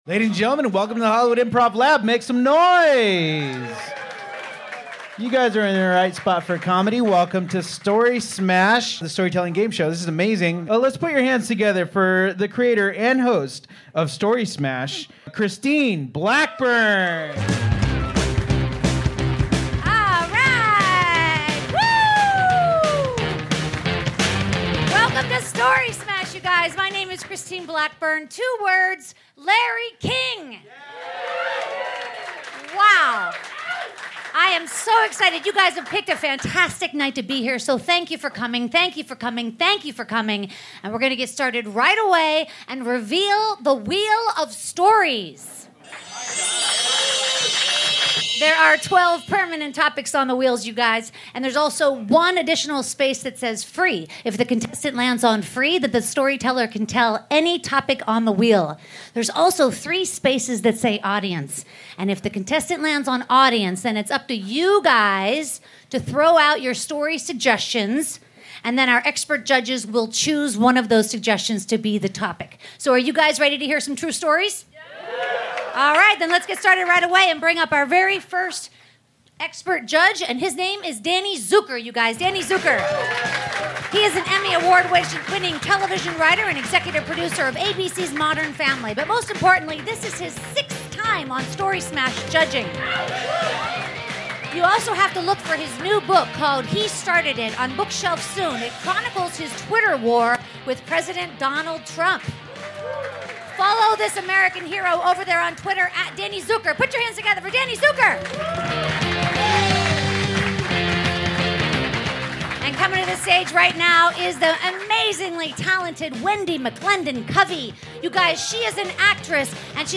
506 - Story Smash The Storytelling Gameshow LIVE at the Hollywood Improv June 23rd!